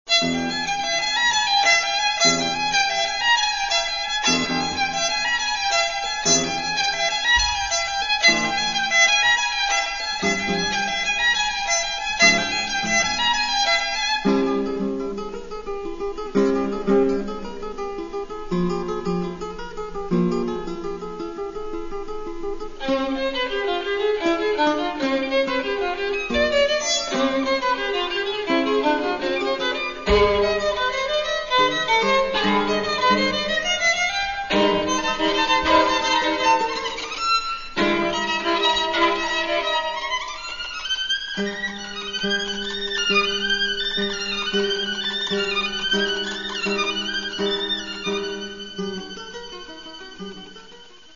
Тому що це – запис живого концерту.